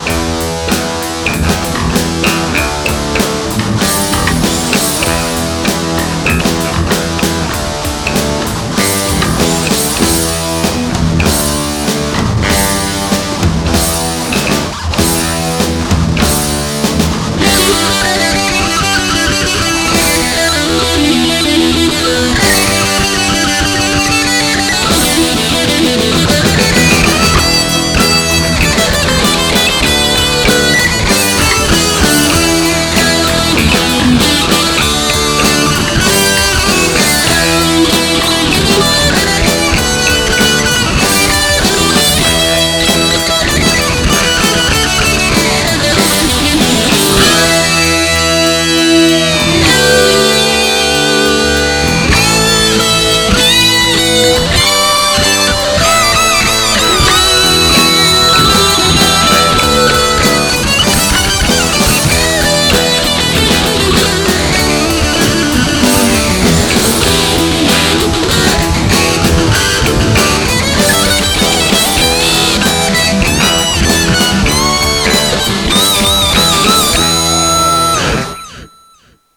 Written back in 1999, I found this on an old demo tape in 2008. Sitting down to re-record it, the drums were still programmed into the Alesis SR-16! I used the same solo/theme as the basis (with enhancements), added bass, and then did the usual multi-tracking on guitars.
Somewhat like Shimmy , this is a fun, groovy song.
The guitar sound is so much better than my recent efforts, I think I've found my tone.